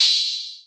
Open Hat 2 [ impact ].wav